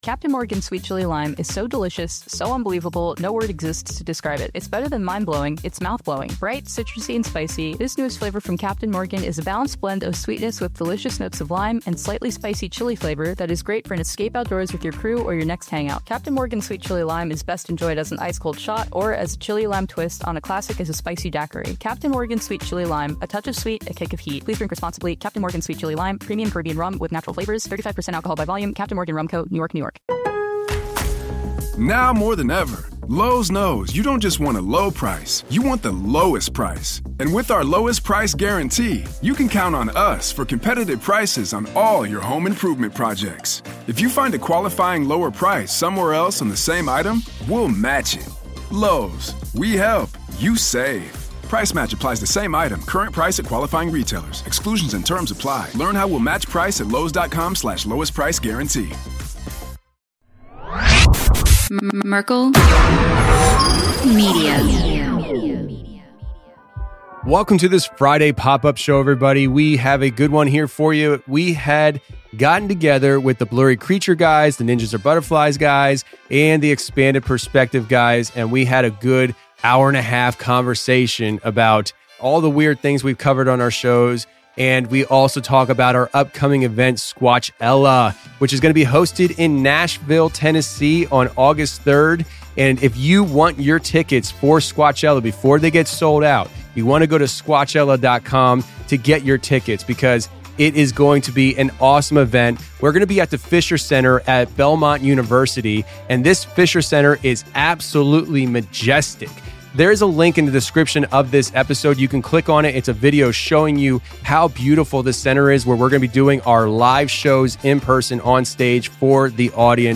They get into the quirky beginnings of this concept, how it came to life, and the spirit of collaboration among different podcasters. The conversation is light-hearted and filled with humorous anecdotes, showcasing the camaraderie and creativity within the paranormal podcast community.